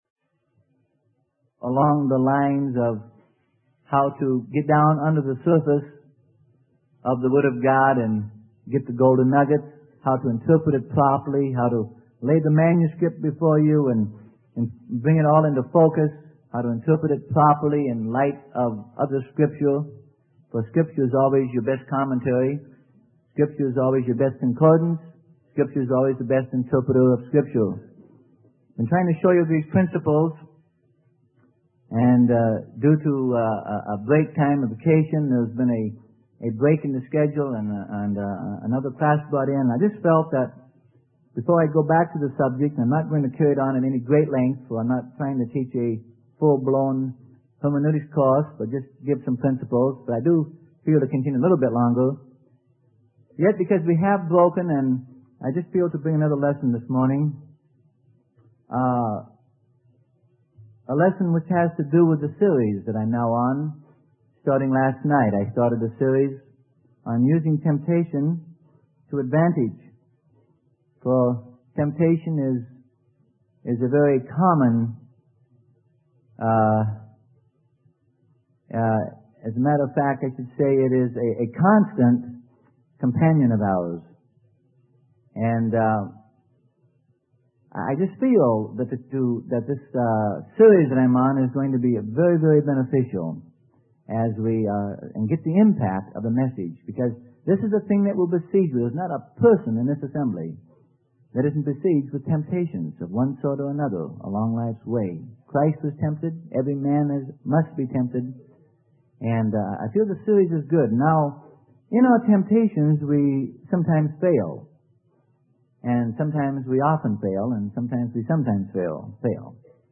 Sermon: How the Body of Christ Should Handle Internal Problems - Freely Given Online Library